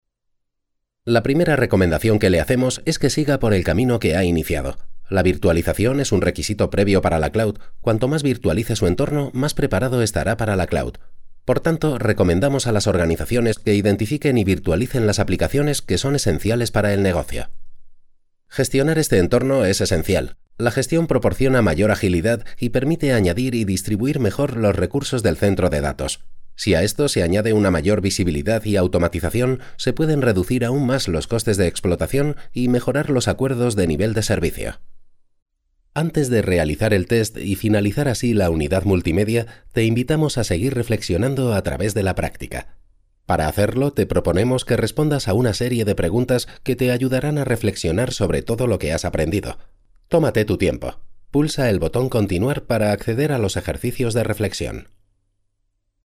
Home studio: Mic AKG C3000 Pre Studio Projects VTB1 Card Tascam US-122MK2 SE Reflection Filter Reaper software edition Vox Studio
Locutor español nativo, voz cálida, e-learning, spots, documental, audiolibros, corporativos
Sprechprobe: eLearning (Muttersprache):
Persuasive voice for advertising. Serious tone and corporate business. And communicative teaching style for e-learning, off and phone voice. Narrative voice warm, friendly and artistic for audiobooks.